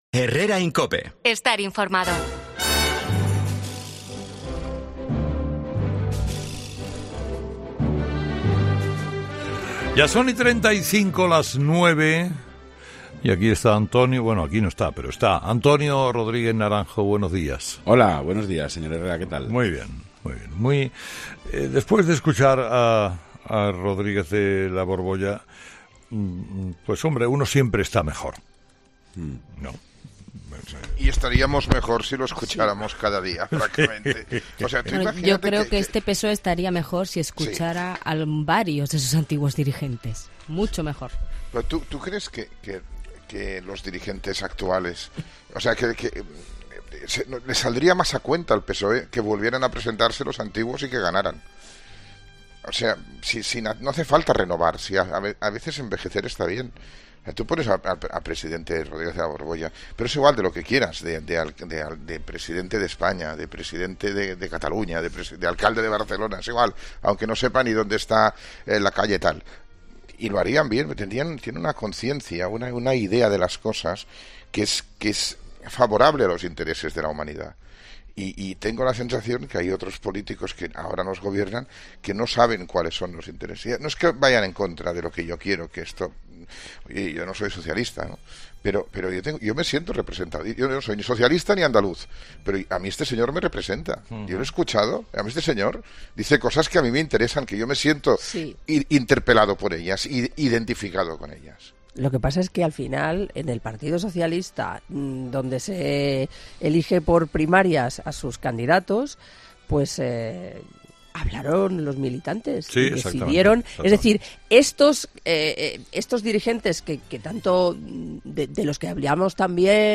AUDIO: Los oyentes, de nuevo, protagonistas en 'Herrera en COPE' con su particular tertulia.